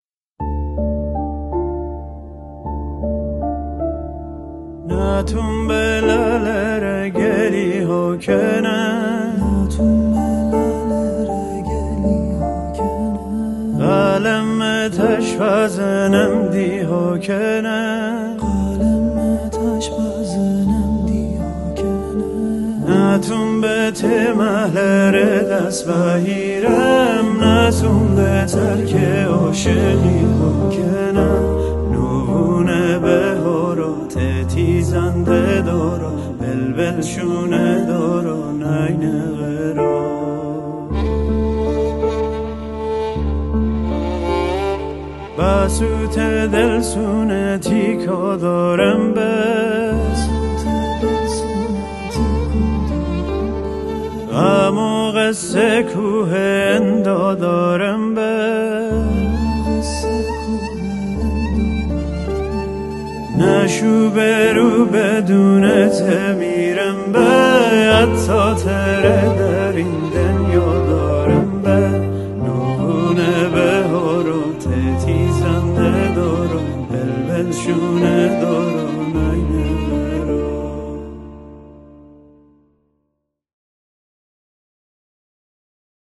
قطعه فولک مازندرانی